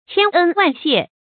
千恩萬謝 注音： ㄑㄧㄢ ㄣ ㄨㄢˋ ㄒㄧㄝ ˋ 讀音讀法： 意思解釋： 一再表示感恩和謝意。